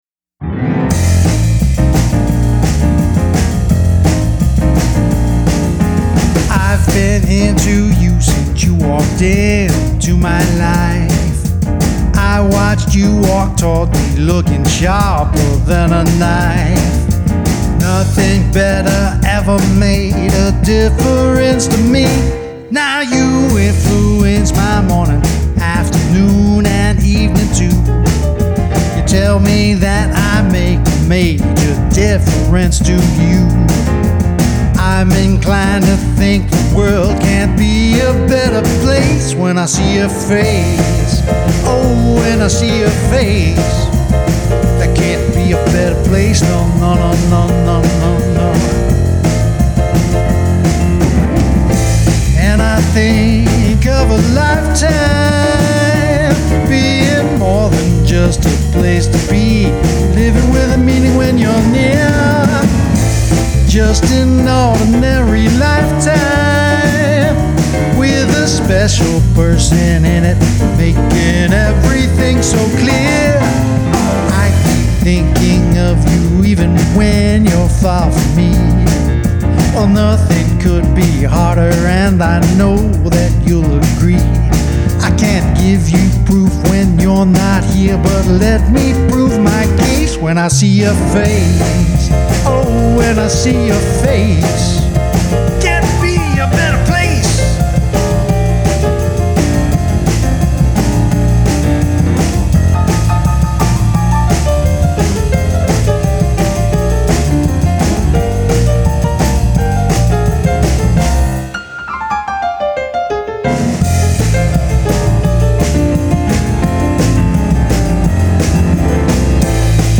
SINGER/MULTI-INSTRUMENTALIST & MUSIC PRODUCER
GRAMMY-recognized jazz musician
pianist, singer, trumpeter, and composer